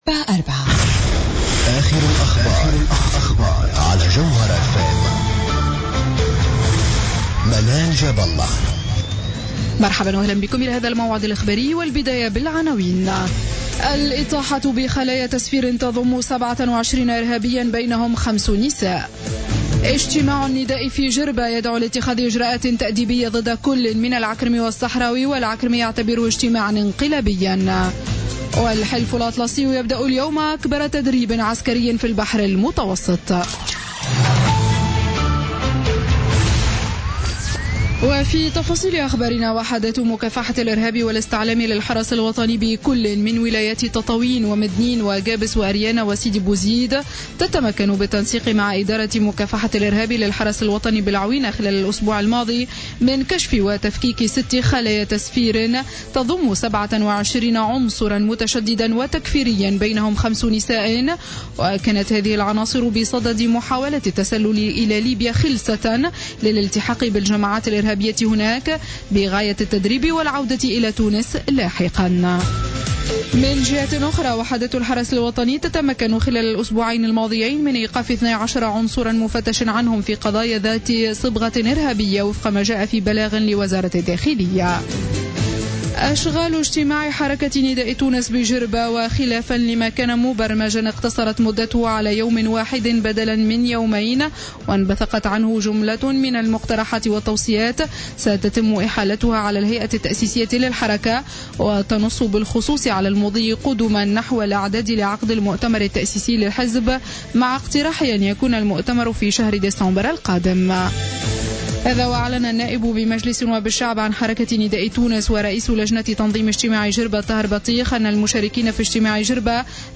نشرة أخبار منتصف الليل ليوم الإثنين 19 أكتوبر 2015